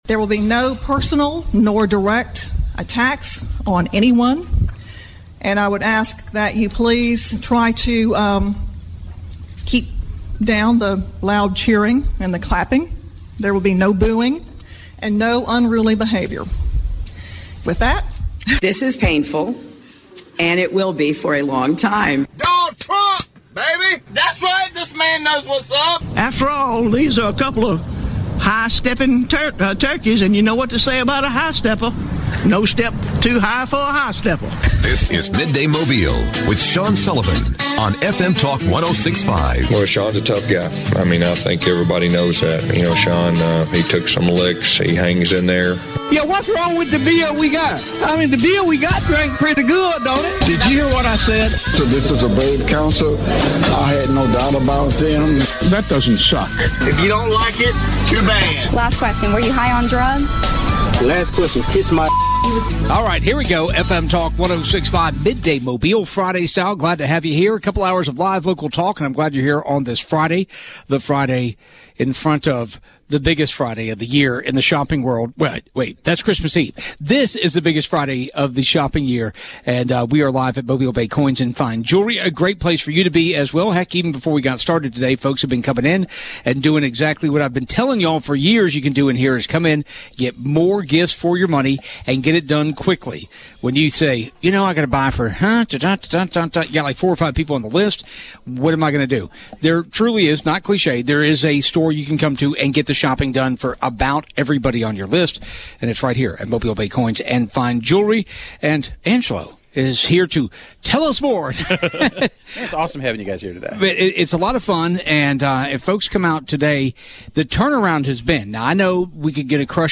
Midday Mobile - Live from Mobile Bay Coin